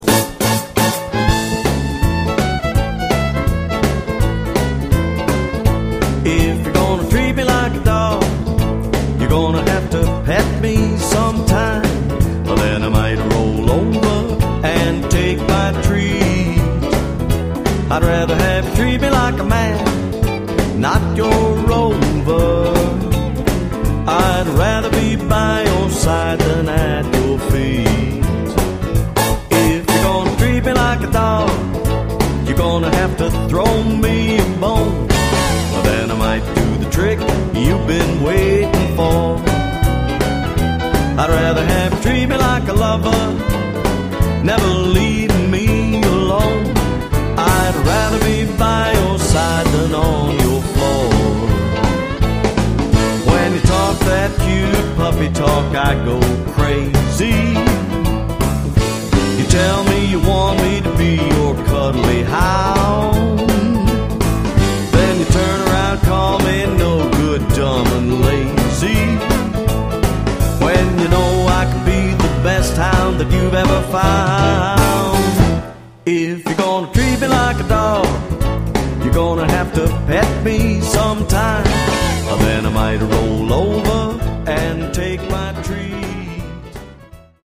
The repertoire covers a wide range of blues styles
jumpin'